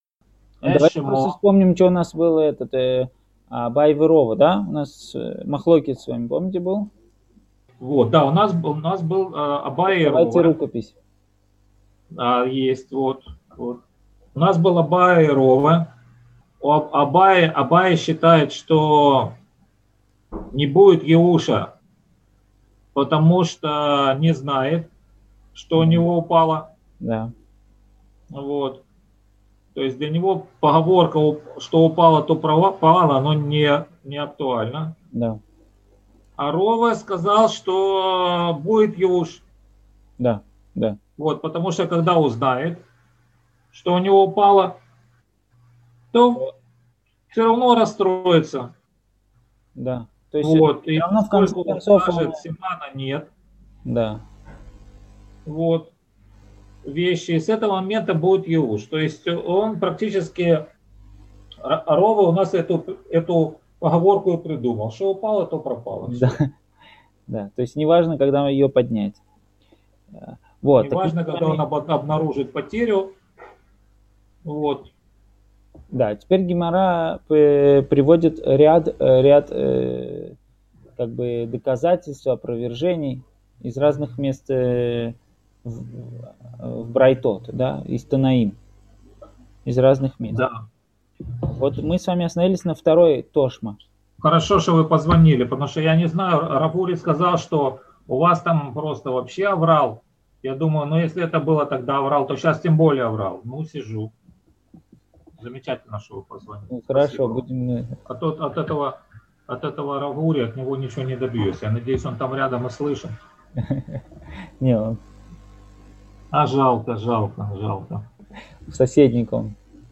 Цикл уроков по трактату Бава Мециа